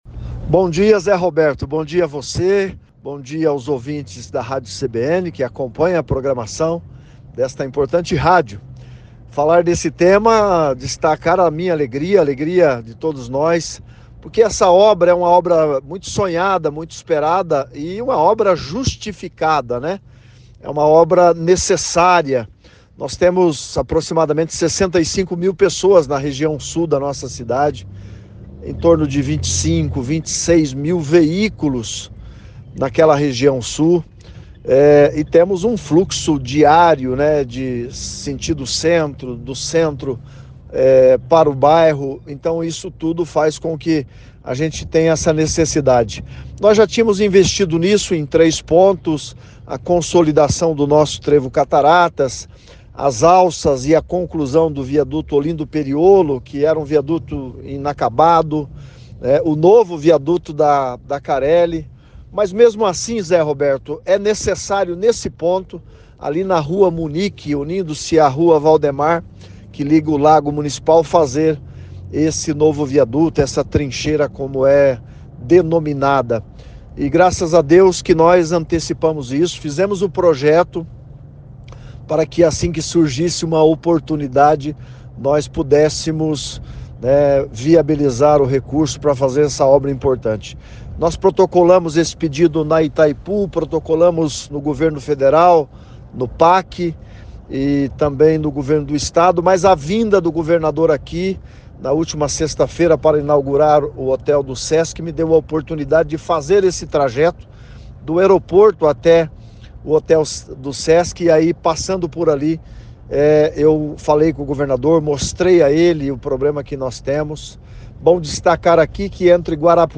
Essa trincheira é necessária, fizemos o projeto, antecipamos, mas a vinda do governador aqui na última sexta-feira (14) foi determinante e a obra vai sair", diz motivado Paranhos, prefeito de Cascavel, em entrevista à CBN.